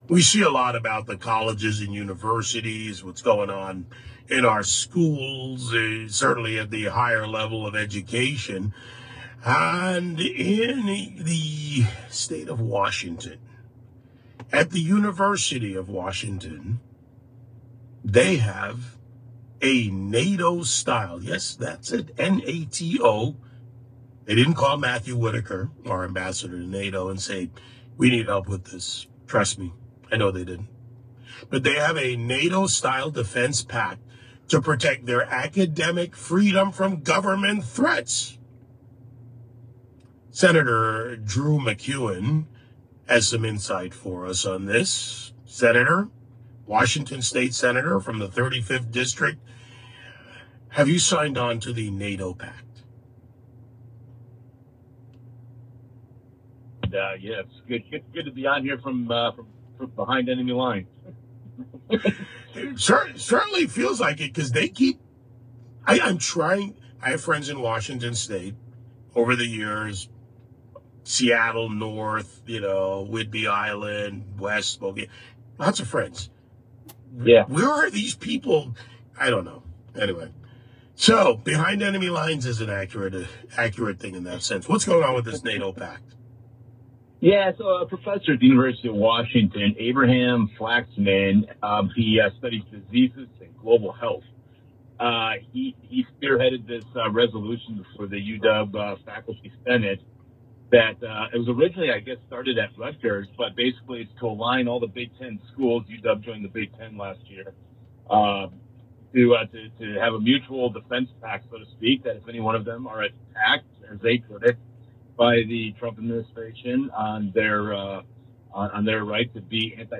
On The David Webb Show, Senator Drew MacEwen delves into a NATO-style pact among Big Ten universities, rising anti-Semitic issues, and Washington’s legislative woes, including tax hikes, erosion of parental rights, and new incentives for striking workers.